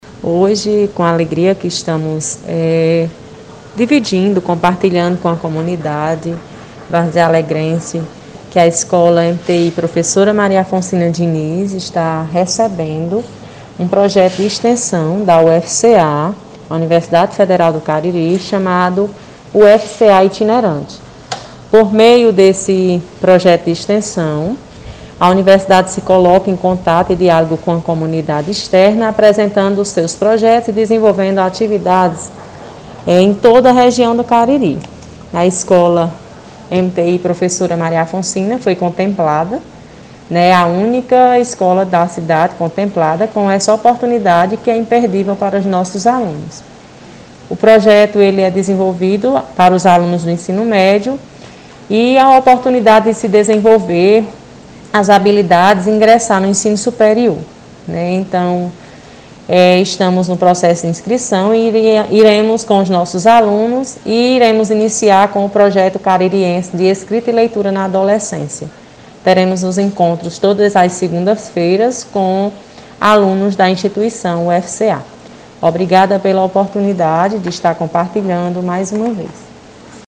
Acompanhe a sonora: